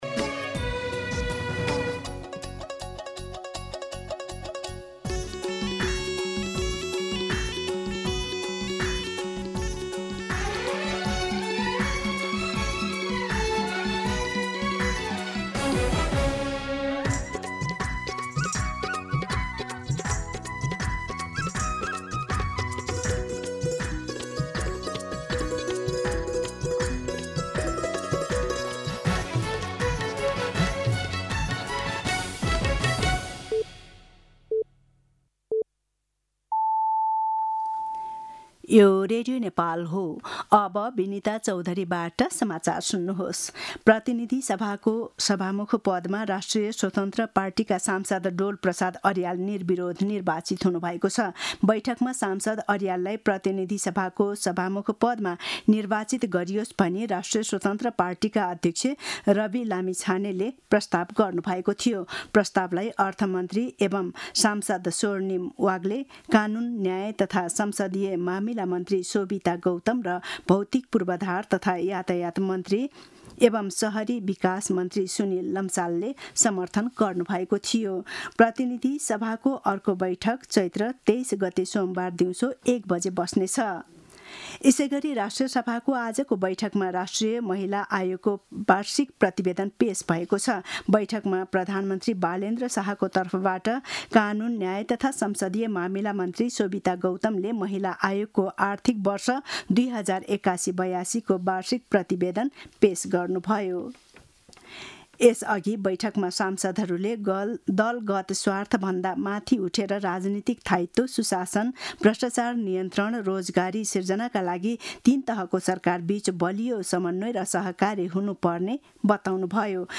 दिउँसो ४ बजेको नेपाली समाचार : २२ चैत , २०८२
4pm-News-22.mp3